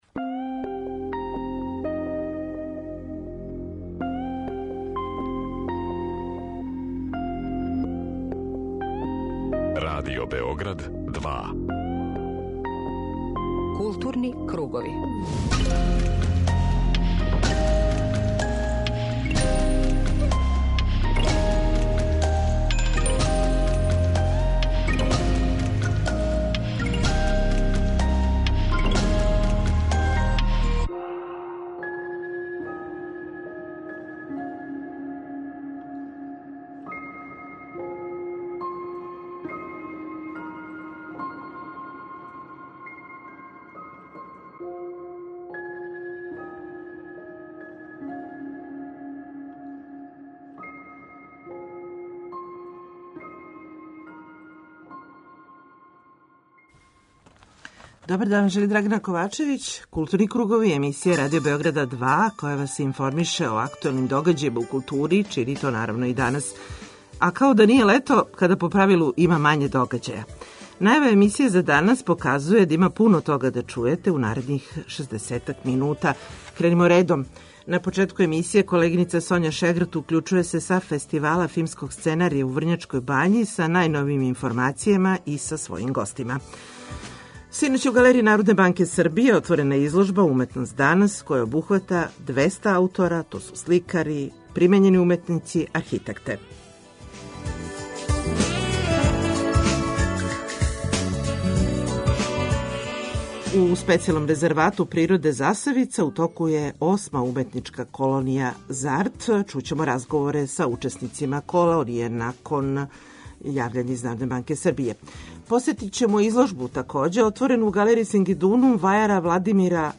Магазин културе Радио Београда 2